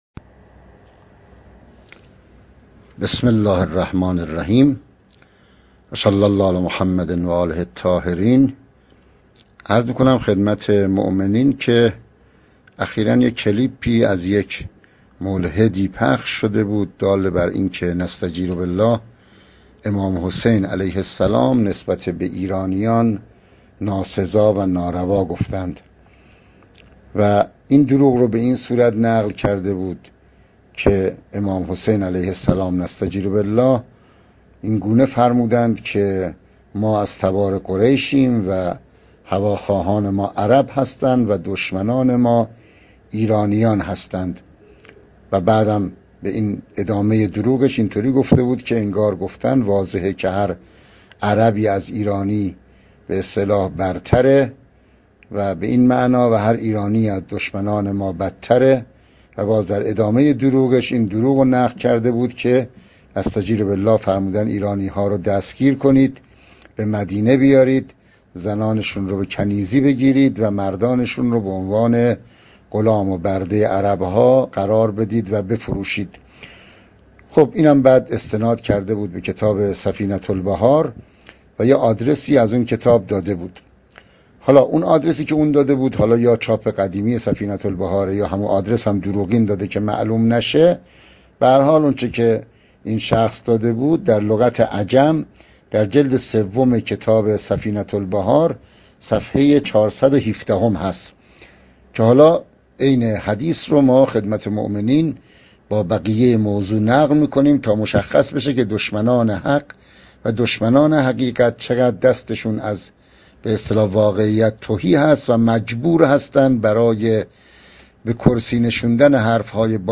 این موضوع چندی پیش توسط یکی از دوستان ارائه شد و بحمدالله به صورت صوتی، تحریف و کذب محض بودن آن ثابت گردید، که عین صوتی مزبور در زیر در اختیار مراجعه کنندگان محترم قرار می گیرد، و در آن سخنان این شخص عینا نقل گردیده و پاسخ داده می شود.
پاسخ صوتی؛ که شدید اللحن تر بودن آن نسبت به سایرموارد، ناشی از شدت دروغ گویی گوینده آن سخنان است: